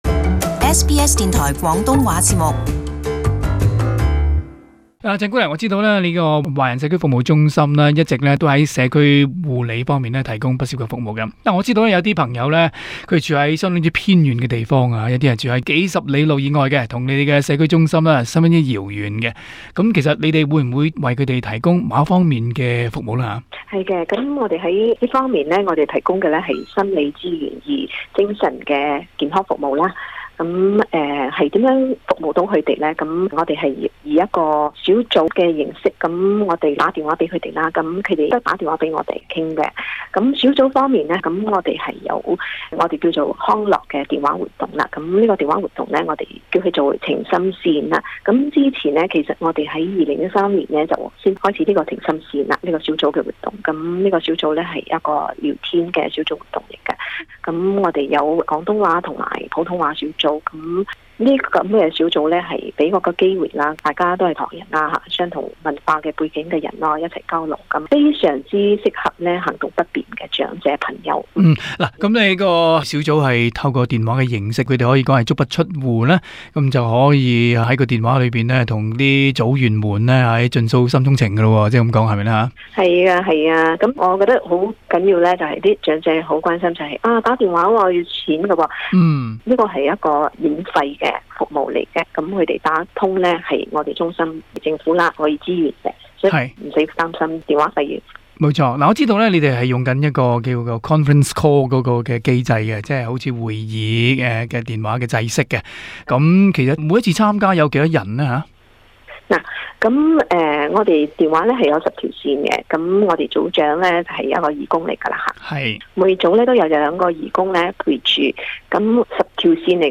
【社團專訪】晴心綫拉近地域隔閡